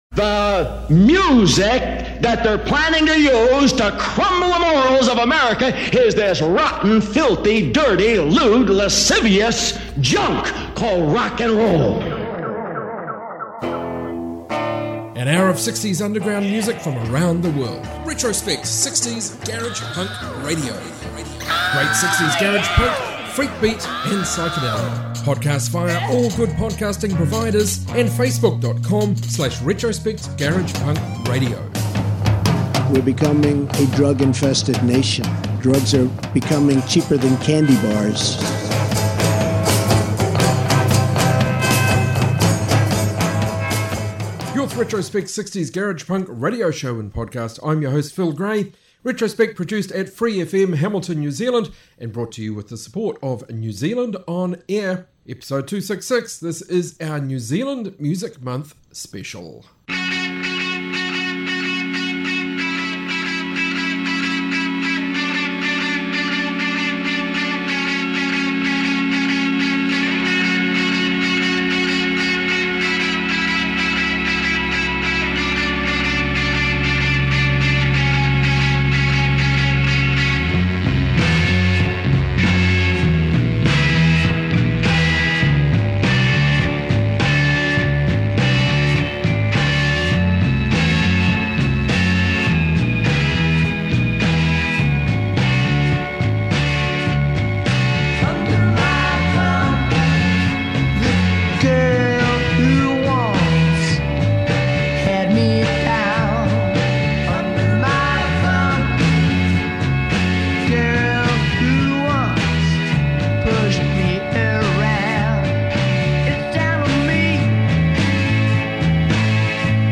60s garage rock garage punk freakbeat & psych